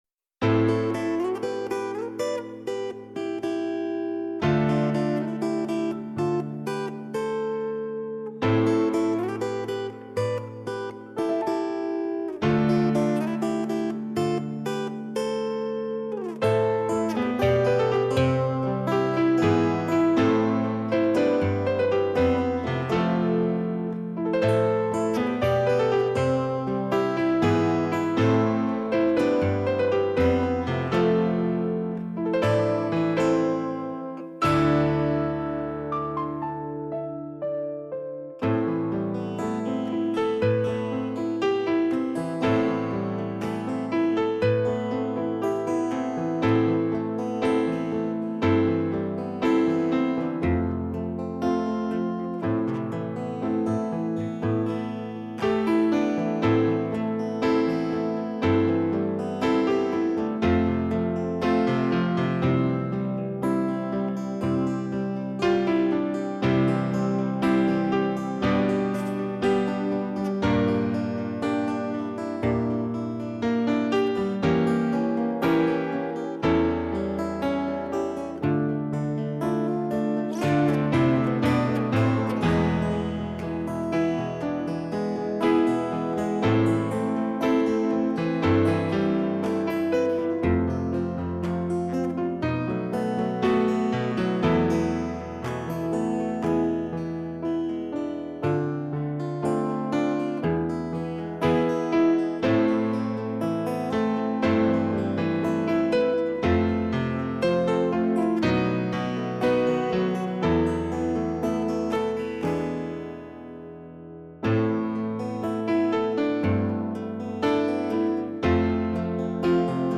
минусовка версия 7220